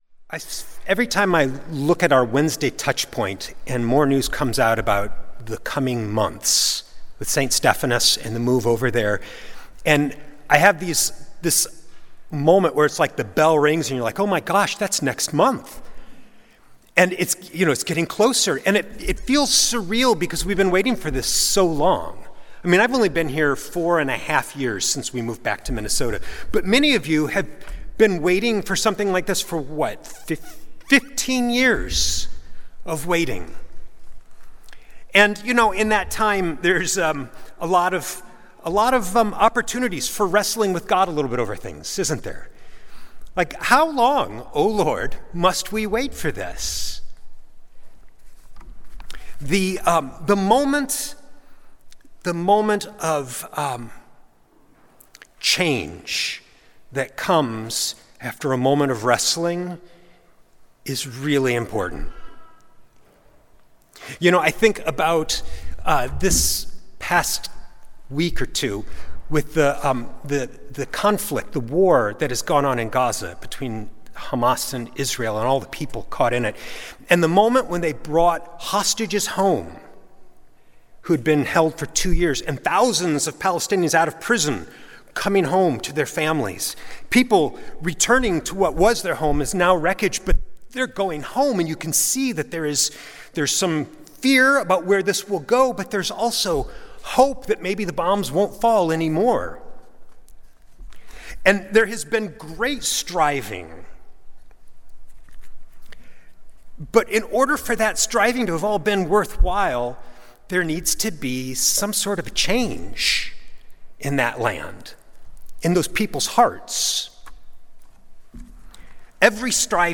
Sunday Worship–October 19, 2025
Sermons